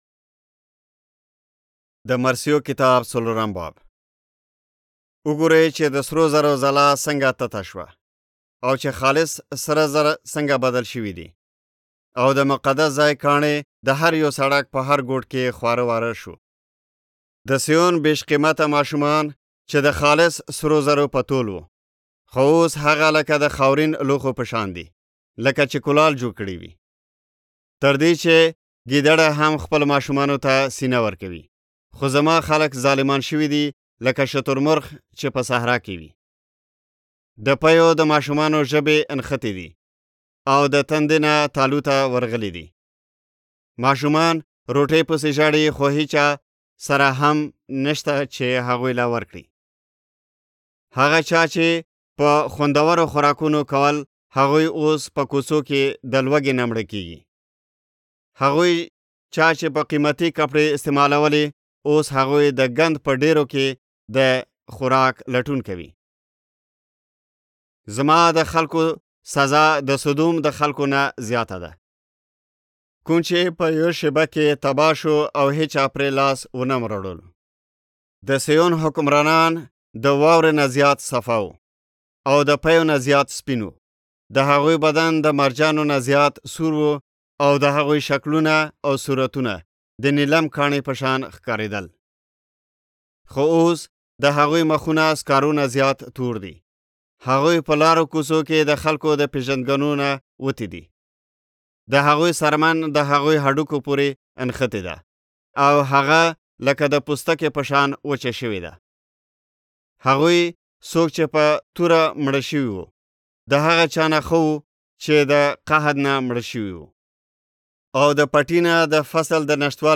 Lamentations Audiobook (YZ) – Pashto Zeray
يوسفزئ ختیځ افغانستان ختیځ افغانستان